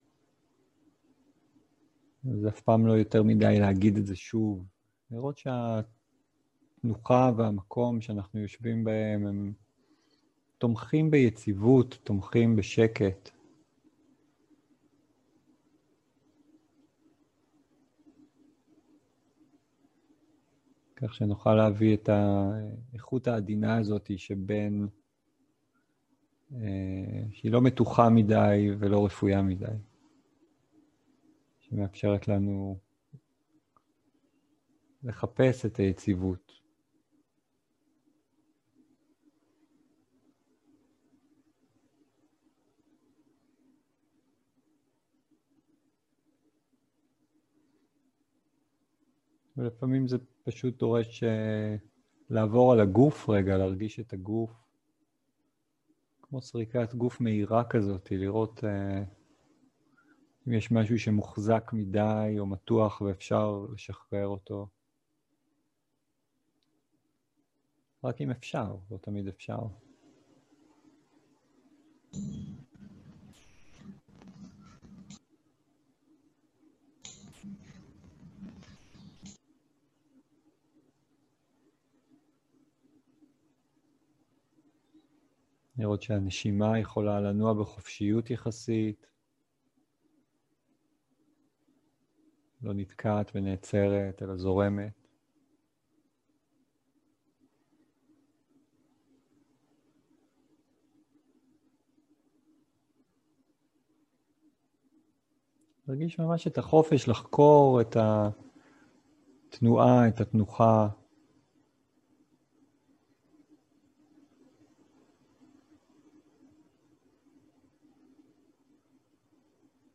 מדיטציה מונחית
איכות ההקלטה: איכות גבוהה